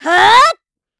Hilda-Vox_Attack5_kr.wav